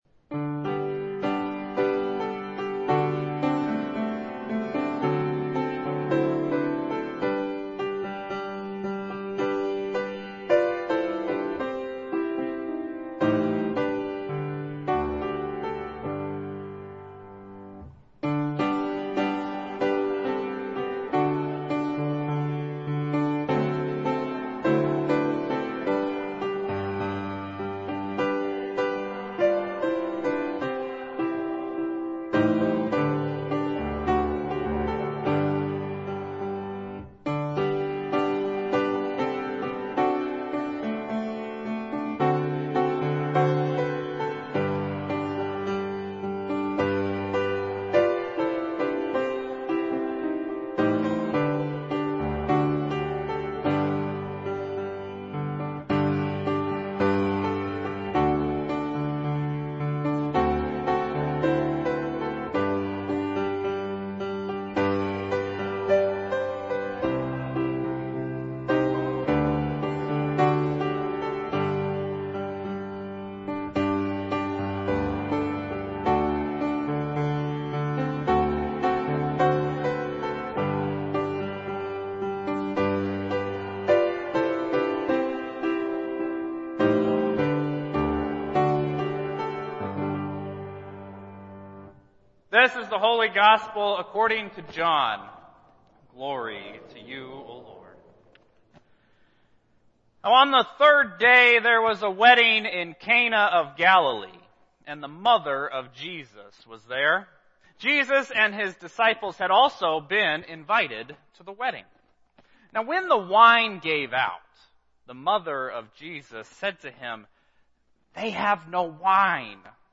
Worship Services | Christ The King Lutheran Church